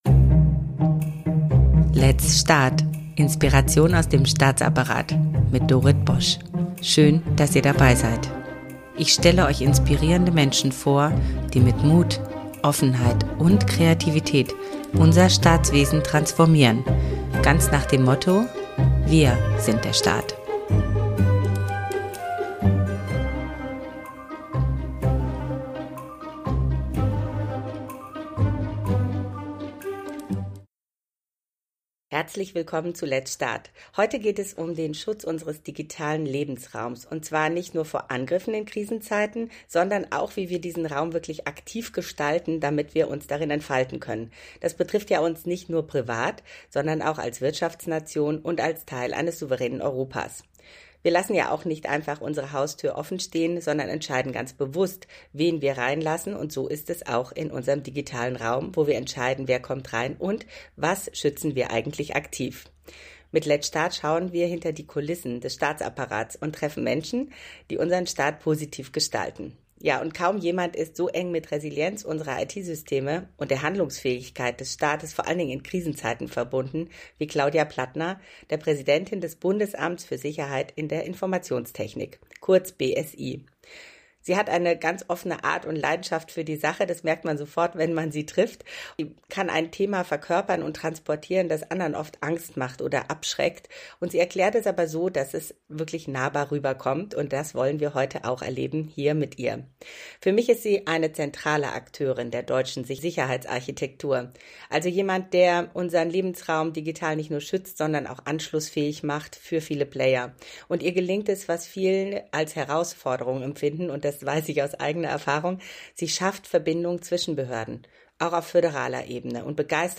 Aber in Wahrheit geht es um etwas viel Grundsätzlicheres: Wie wollen wir unseren digitalen Lebensraum nicht nur schützen, sondern auch gestalten? In dieser Folge spreche ich mit Claudia Plattner, Präsidentin des Bundesamtes für Sicherheit in der Informationstechnik.